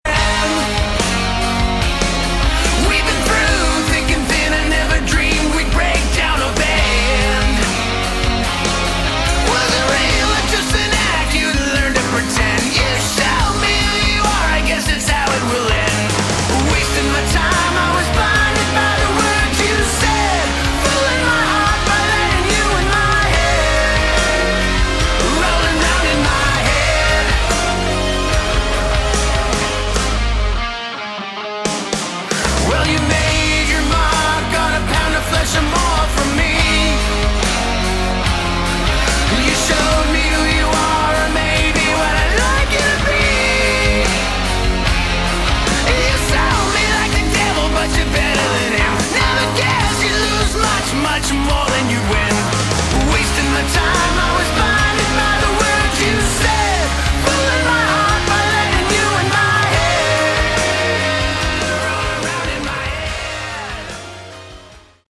Category: Hard Rock
Lead Vocals
Lead Guitar, Vocals
Bass
Drums
Keys, Vocals